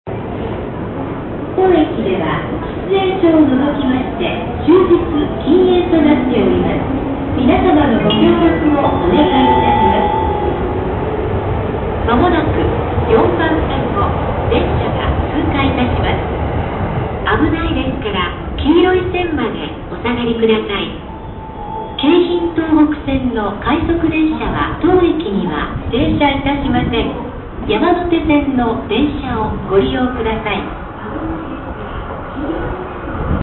放送も、通常の通過放送の後に一文を追加して山手線に乗車するように注意を促している。
通過放送快速通過駅の通過放送です。
keihin-tohoku-rapidpass.mp3